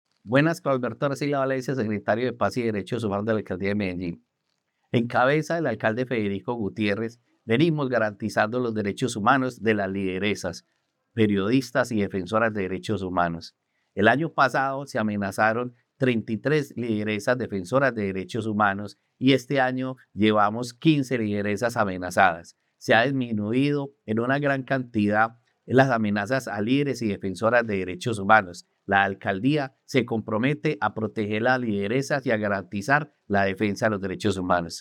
Palabras de Carlos Arcila, secretario de Paz y Derechos Humanos Para proteger a lideresas sociales amenazadas, la Alcaldía de Medellín ofrece alojamiento temporal como medida de asistencia, atención y protección.